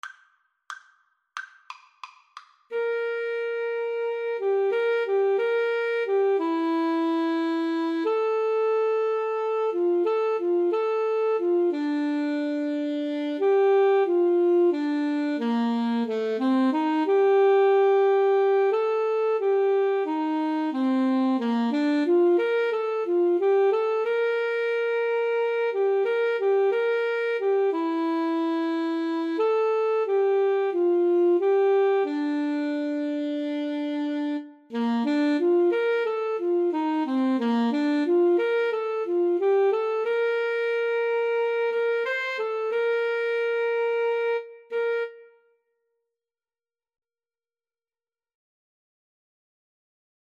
Moderato = c.90
2/2 (View more 2/2 Music)